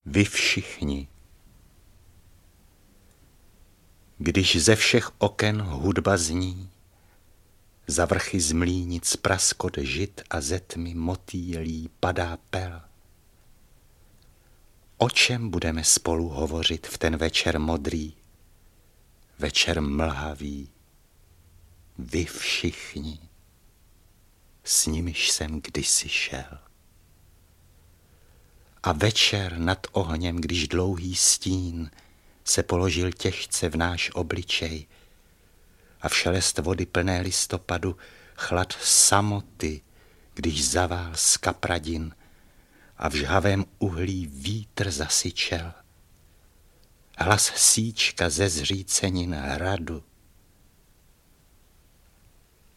Interpreti:  Josef Hora, Josef Chvalina
beletrie / poezie
AudioKniha ke stažení, 6 x mp3, délka 9 min., velikost 7,7 MB, česky